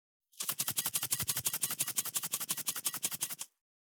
367,調味料固形物,カシャカシャ,サラサラ,パラパラ,ジャラジャラ,サッサッ,ザッザッ,シャッシャッ,
効果音厨房/台所/レストラン/kitchen